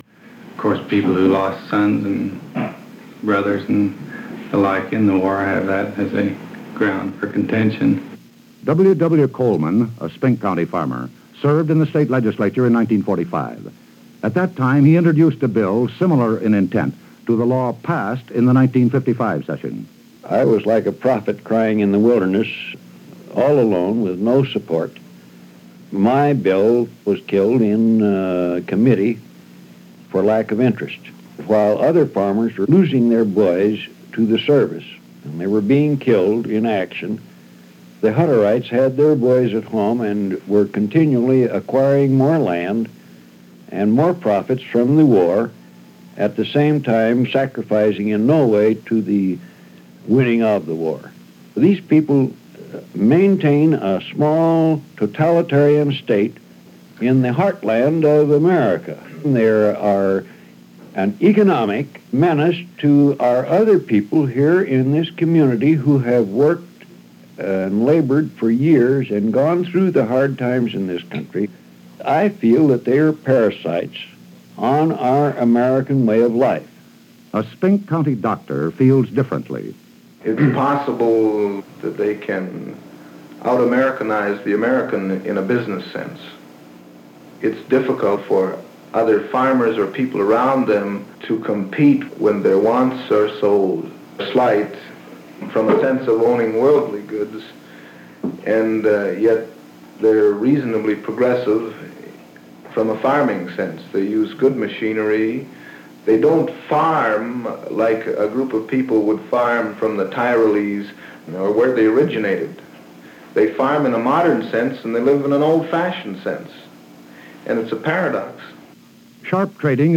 The Great Radio Documentaries - "The Hutterites" - Edward R. Murrow 1958 - Past Daily Pop Chronicles
This documentary, part of the weekly Hidden Revolution series for CBS radio in 1958, was narrated by the legendary Edward R. Murrow . He discusses the issues facing the Hutterite community and their relationships with non-Hutterites who live close-by.